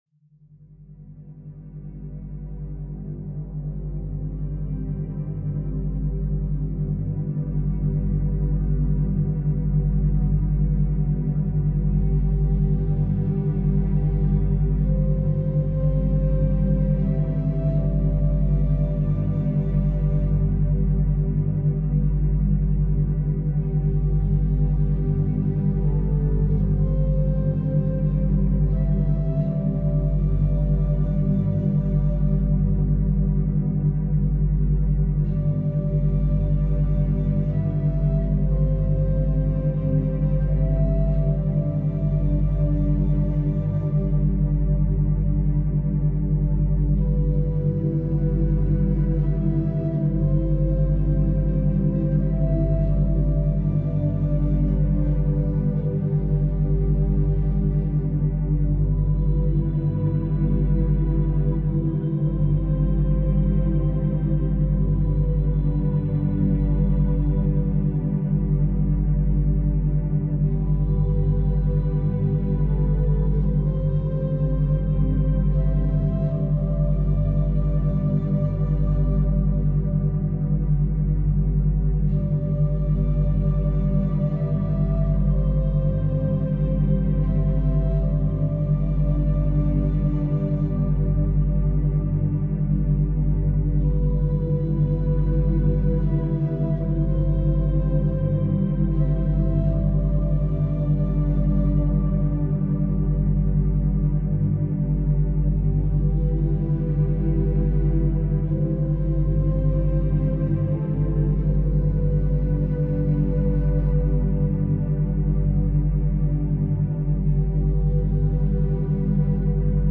Accelerated Learning – Focus and Memory Boost with Monaural Beats